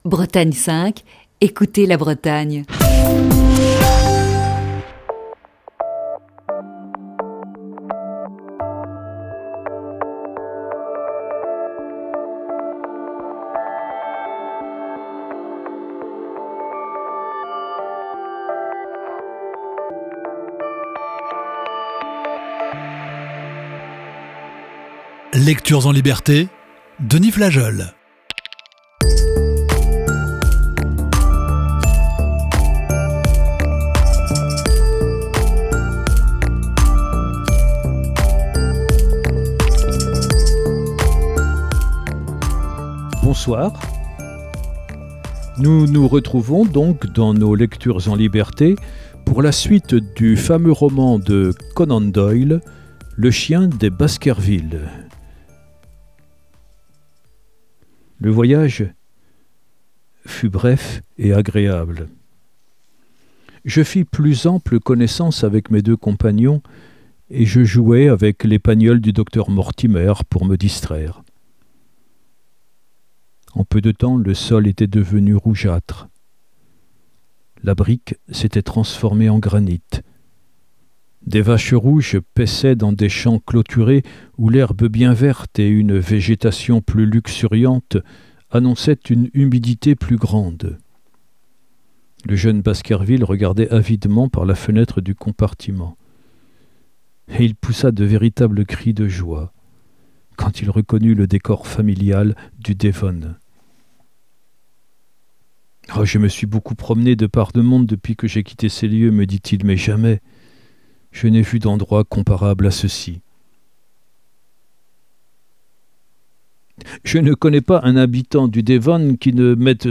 la lecture d'un classique de la littérature